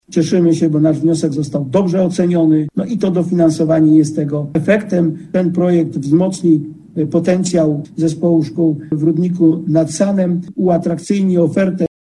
Mówi starosta niżański Robert Bednarz